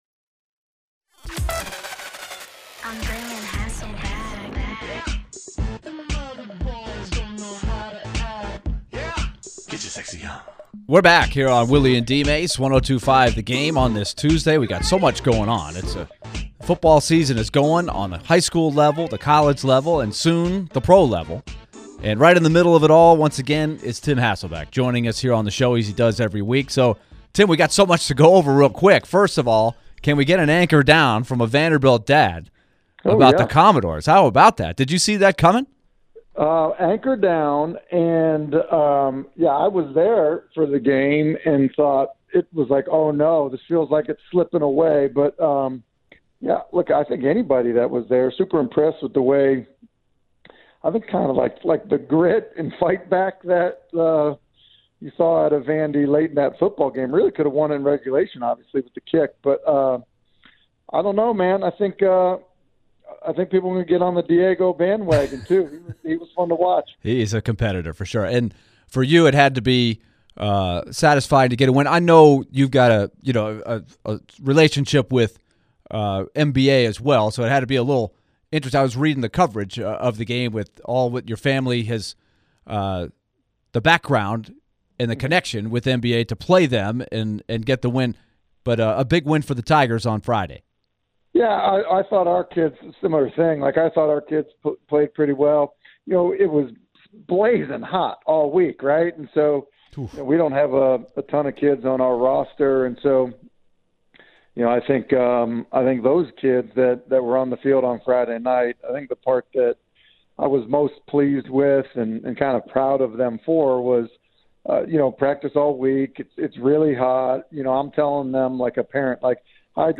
ESPN NFL analyst and Ensworth head football coach joined the show to discuss Vanderbilt's upset over Virginia Tech and the Titans' upcoming matchup with the Bears on Sunday.